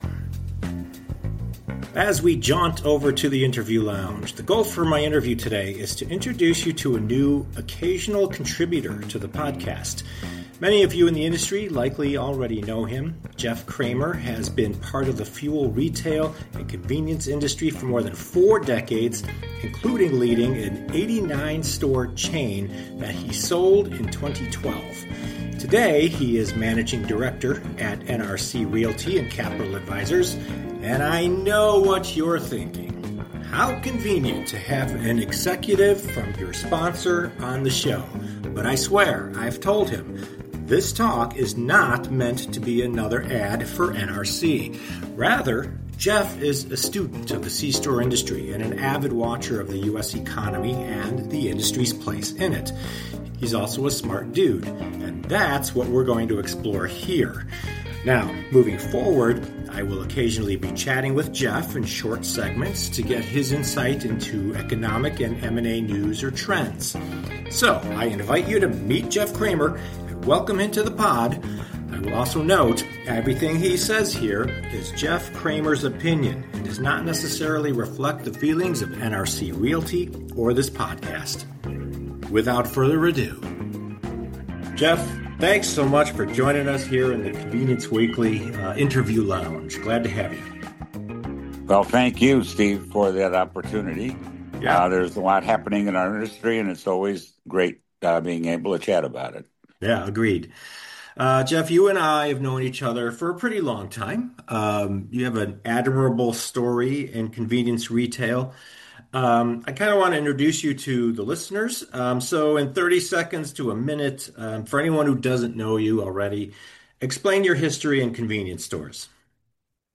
My interview